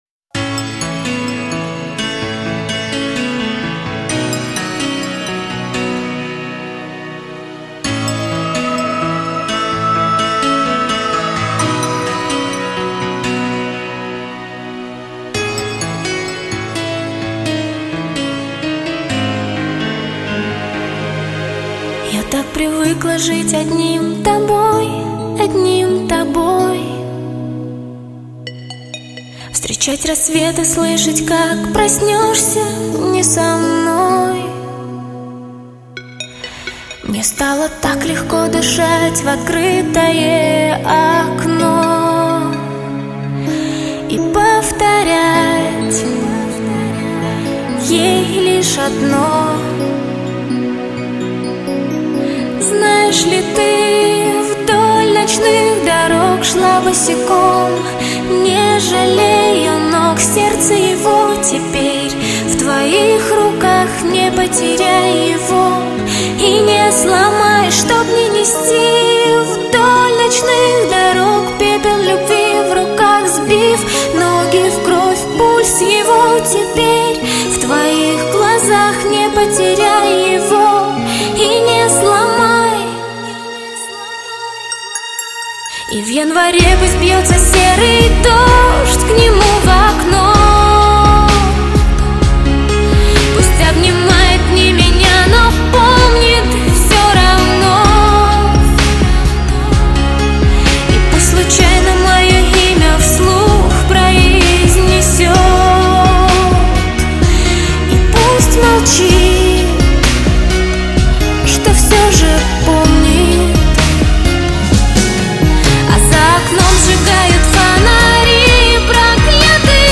轻脆、干净的声音
她并不是一位抒情式的女歌手，在她的歌声中总是蕴涵着强烈地爆发力。
她的歌曲从曲风到歌词都是节奏感鲜明，使人过目（耳）不忘。